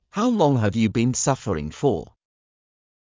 ﾊｳ ﾛﾝｸﾞ ﾊﾌﾞ ﾕｳ ﾋﾞｰﾝ ｻｯﾌｧﾘﾝｸﾞ ﾌｫｰ